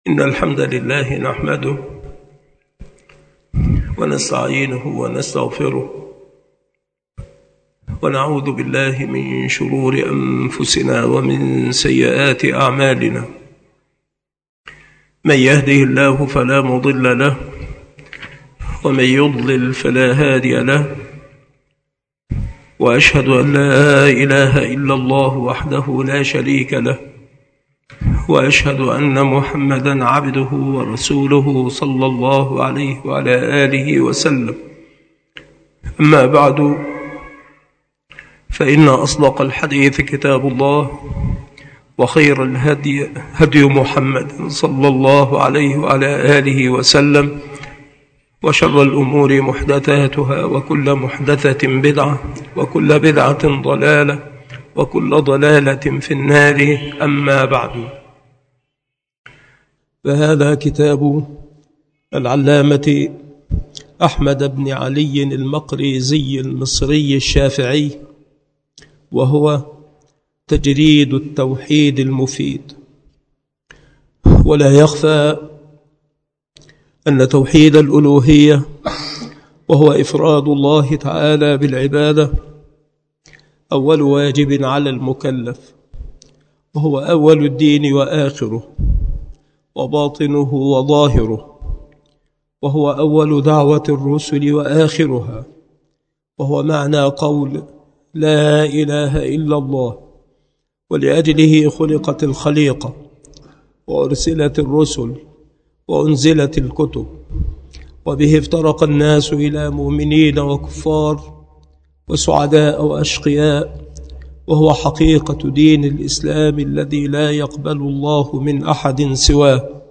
مكان إلقاء هذه المحاضرة بالمسجد الشرقي بسبك الأحد - أشمون - محافظة المنوفية - مصر عناصر المحاضرة : ترجمة مختصرة للمؤلف. ثناء العلماء على الرسالة. الالتفات إلى الأسباب على قسمين. أنواع المحبة.